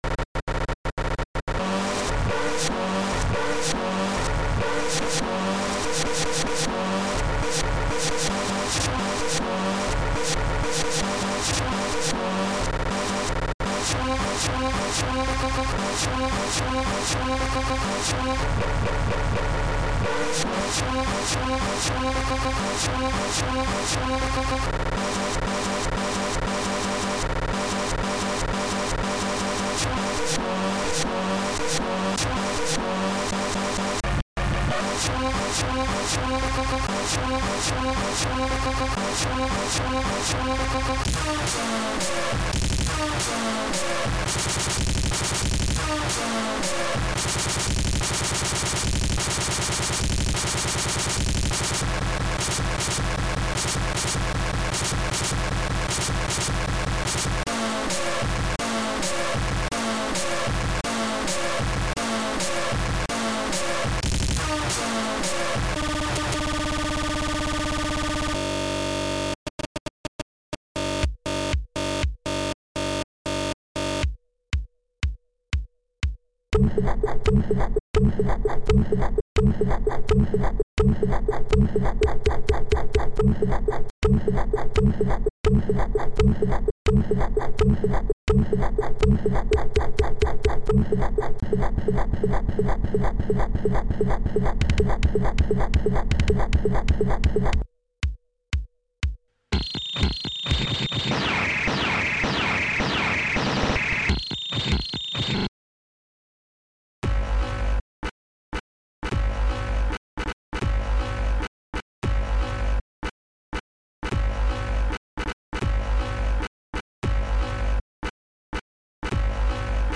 weird, experimental, glitch,